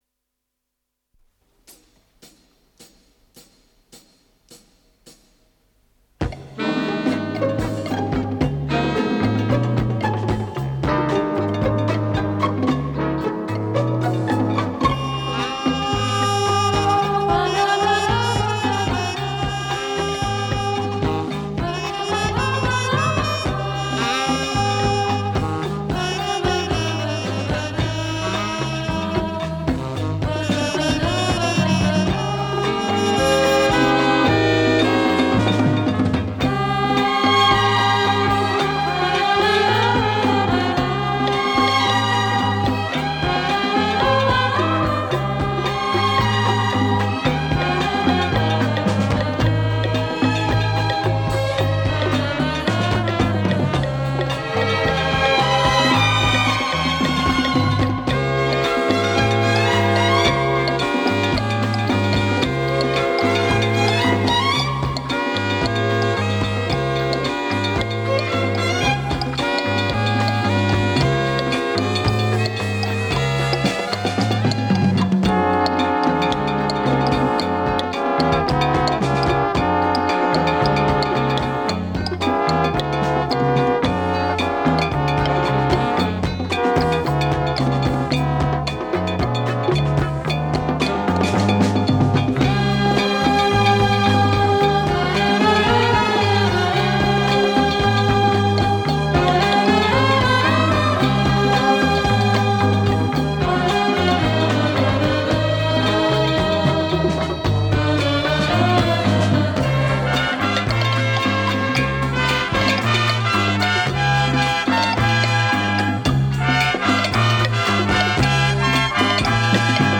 Эстрадно -- симфонический
Вокальный  ансамбль. Запись 1978года.